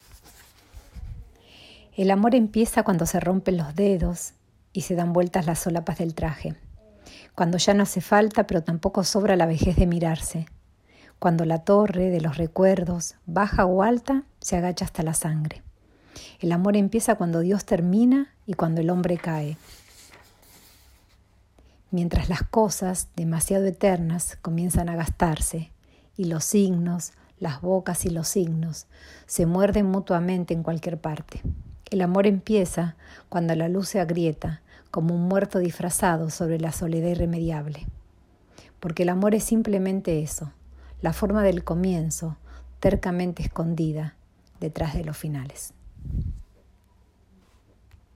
nutricionista y amiga del psicoanálisis- nos lee un poema de Roberto Juarroz que lo nombramos con su primer verso